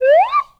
Comedy_Cartoon
whistle_slide_up_04.wav